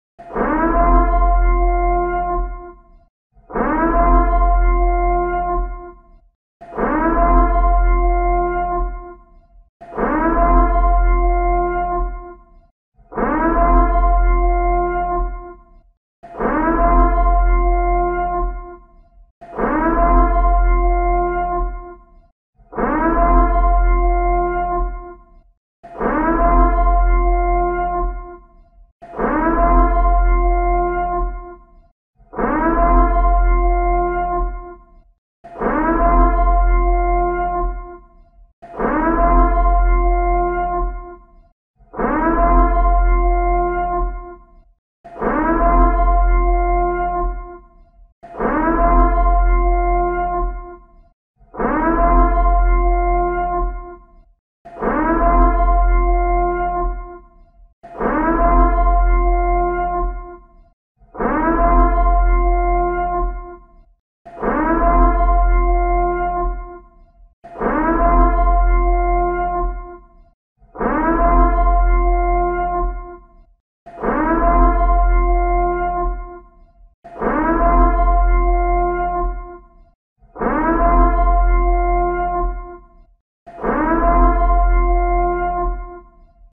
Purge Siren Sound Effect (HD).mp3
purge-siren-sound-effect-hd.mp3